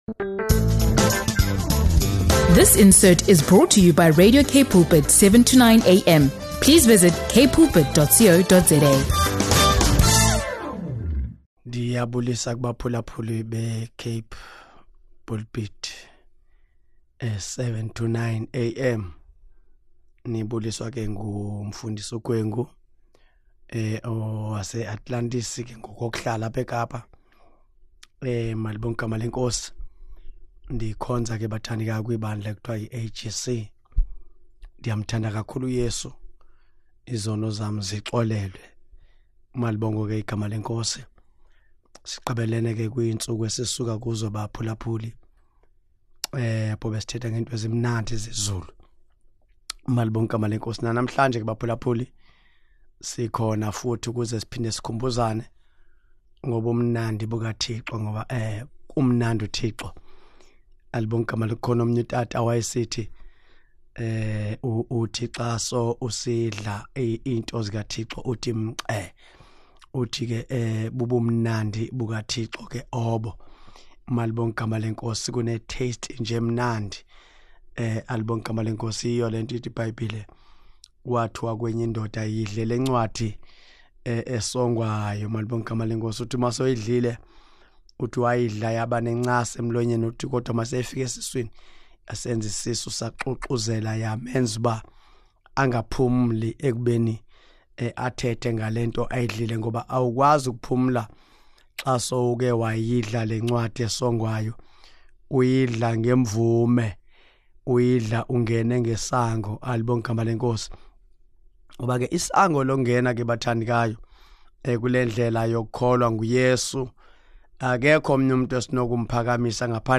He opens with greetings to the congregation, encouraging a spirit of unity and reflection.
He discusses the significance of scripture, urging the community to seek guidance and understanding through the Word. With heartfelt exhortations, he motivates believers to rely on Jesus for strength and purpose in their lives.